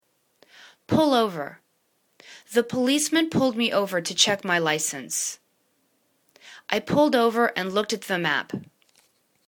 pull over     /pol ovər/    phrasal verb